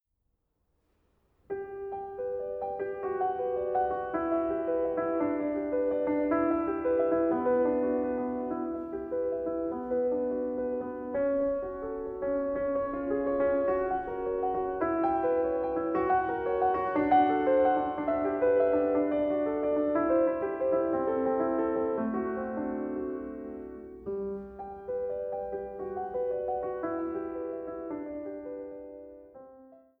Klavier